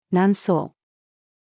南宋-female.mp3